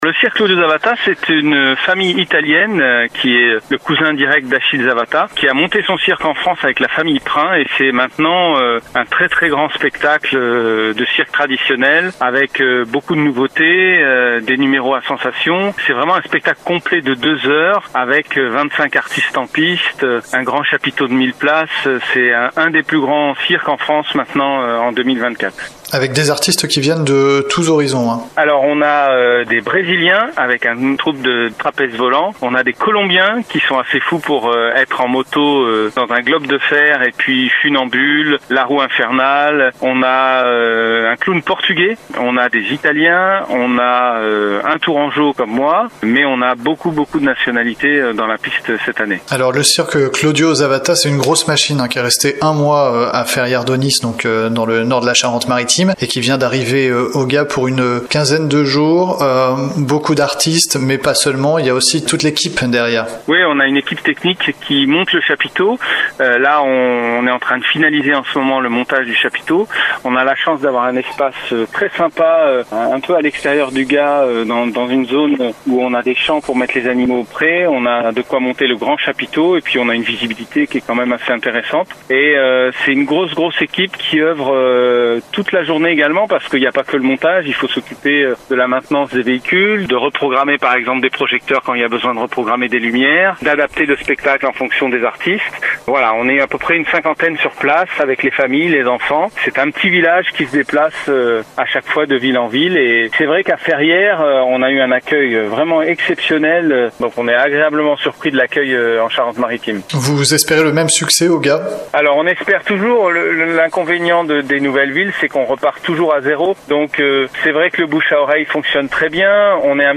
Il a planté son chapiteau pour une quinzaine de jours, en espérant rencontrer le même succès qu’en terres aunisiennes, avec son nouveau spectacle 2024 qui rassemble une vingtaine d’artistes venus du monde entier. Entretien